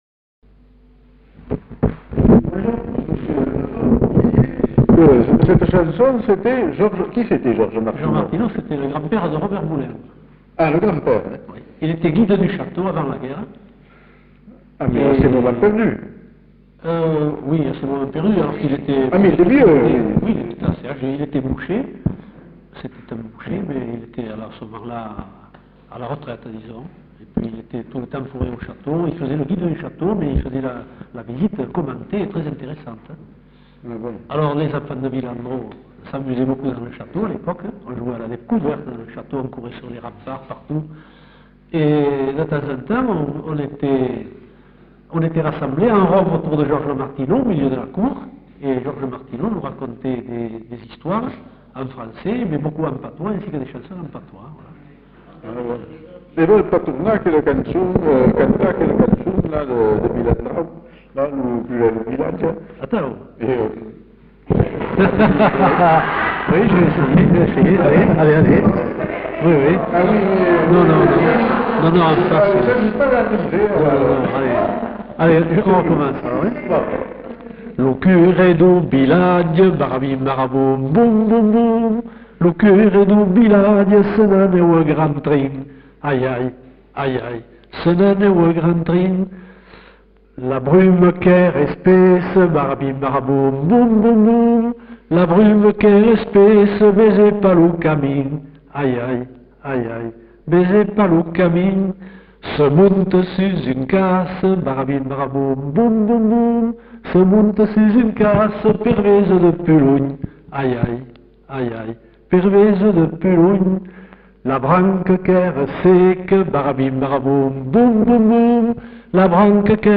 Aire culturelle : Bazadais
Lieu : Villandraut
Genre : chant
Effectif : 1
Type de voix : voix d'homme
Production du son : chanté